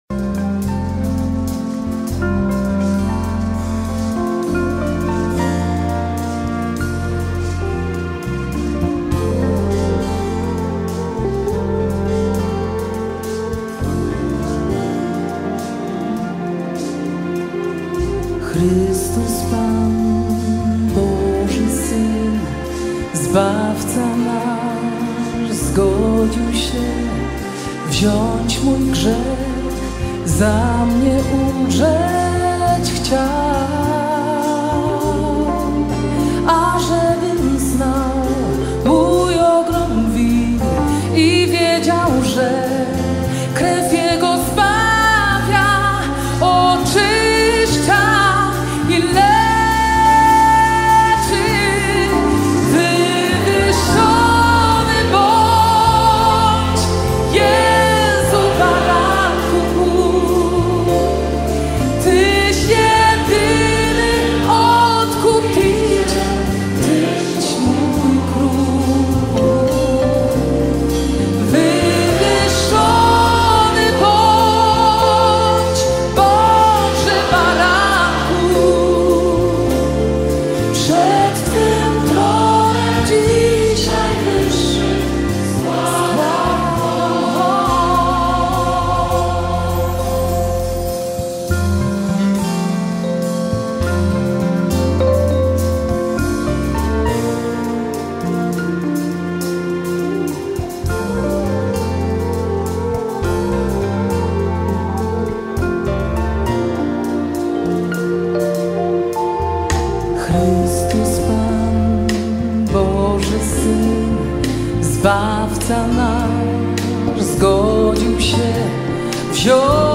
Rzeszów (Live)